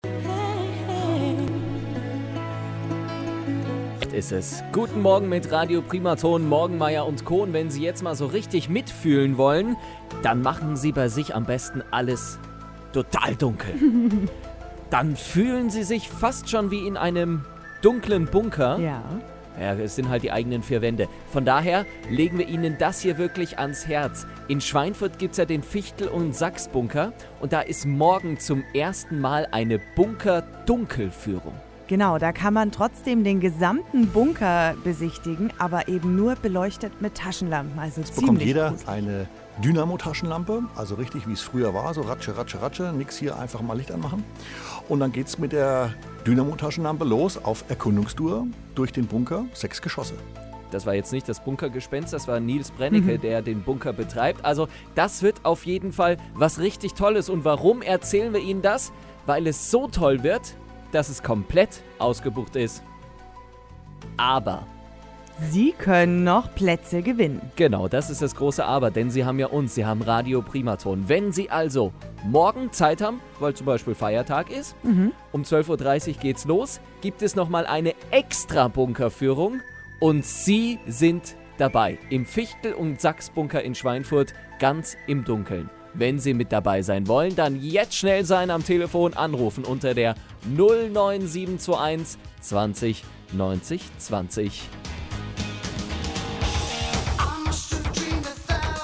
Radio Primaton 1. Dunkelführung durch den Bunker